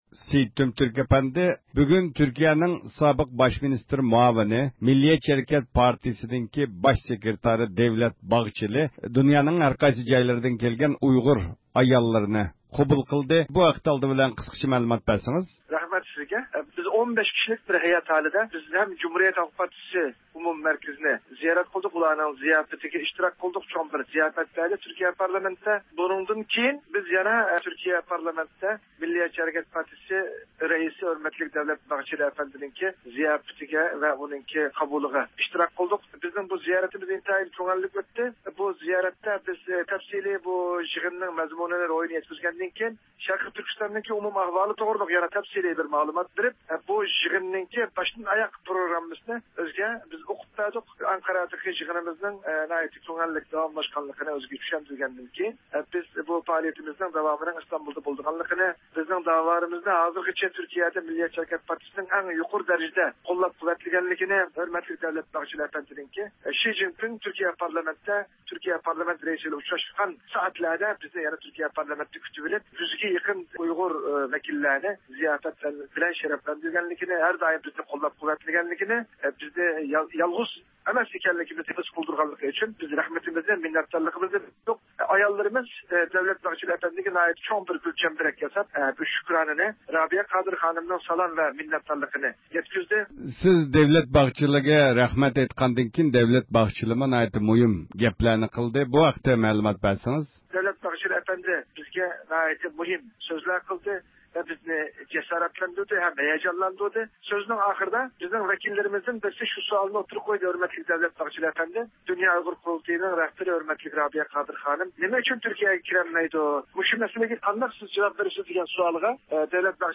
ئىختىيارىي مۇخبىرىمىز
بۇ ئۇچرىشىش ھەققىدە تېخىمۇ تەپسىلىي مەلۇمات ئېلىش ئۈچۈن دۇنيانىڭ ھەرقايسى جايلىرىدىن كەلگەن كۇرس ئەھلىلىرى بىلەن سۆھبەت ئېلىپ باردۇق.